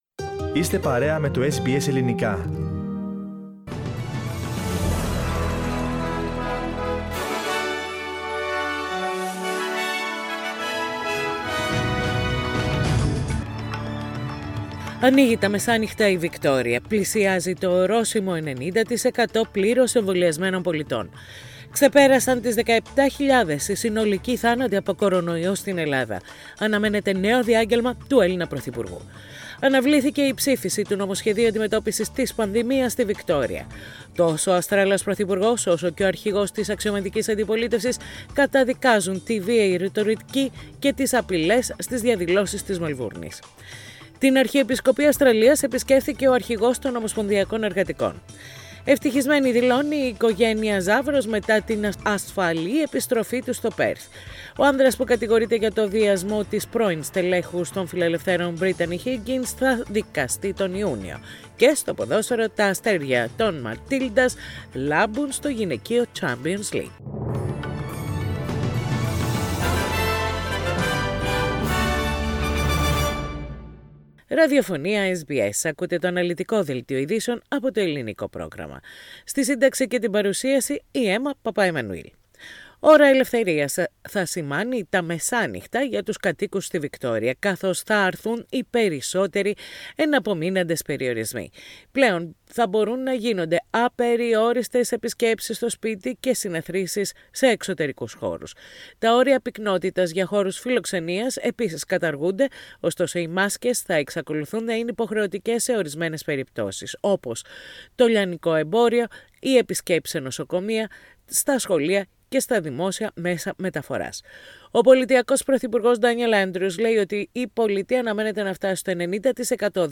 Ειδήσεις στα Ελληνικά - Πέμπτη 18.11.21
Οι κυριότερες ειδήσεις της ημέρας από το Ελληνικό πρόγραμμα της ραδιοφωνίας SBS.